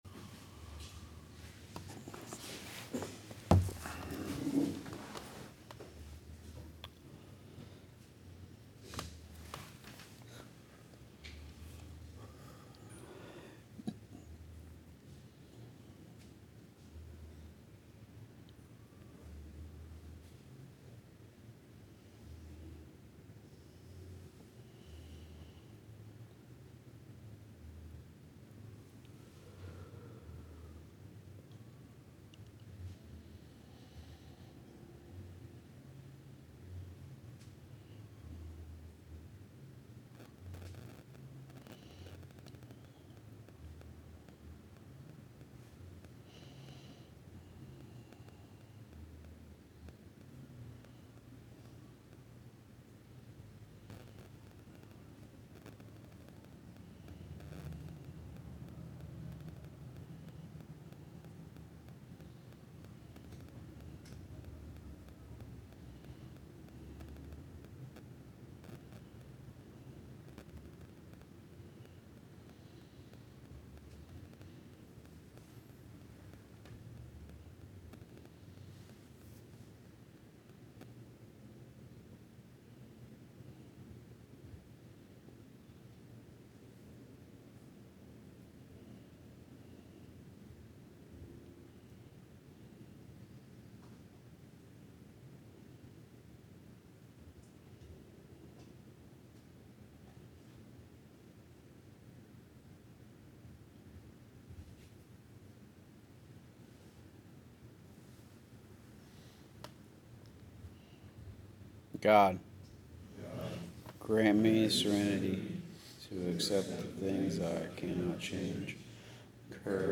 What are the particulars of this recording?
Speaking in Newnan, GA